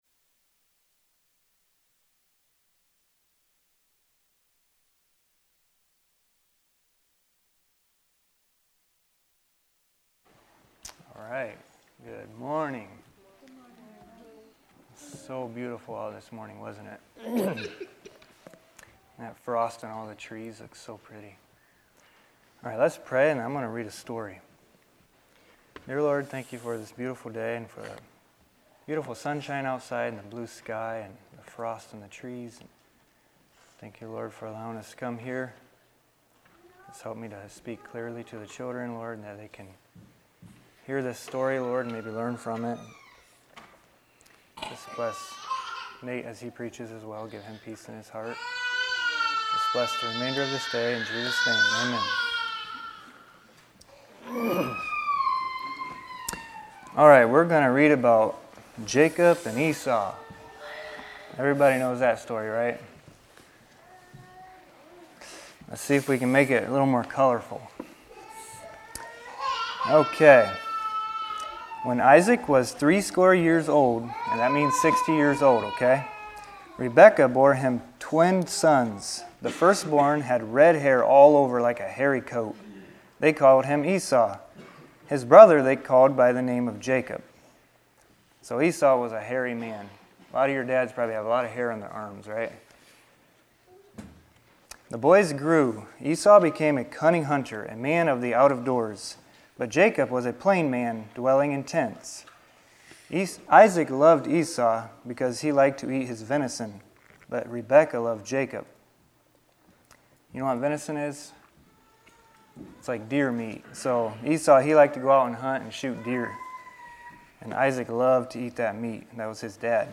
Children's Lessons